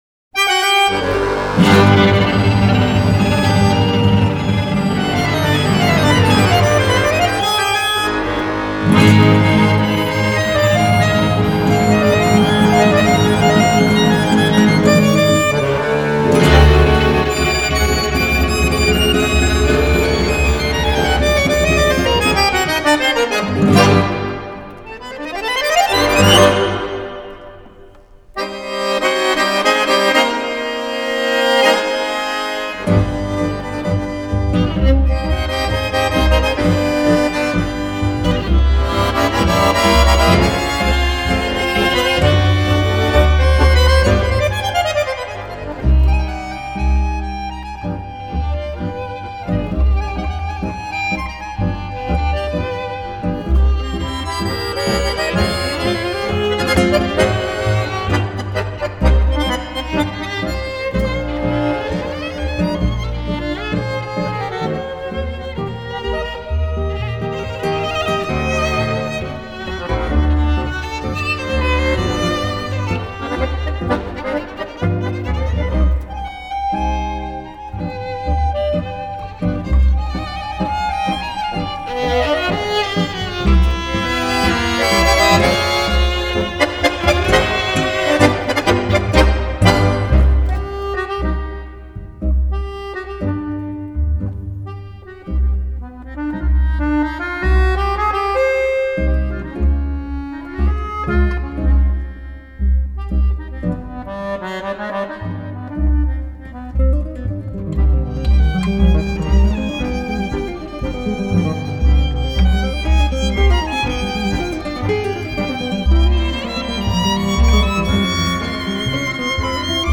который состоял из баяна, скрипки, гитары и контрабаса.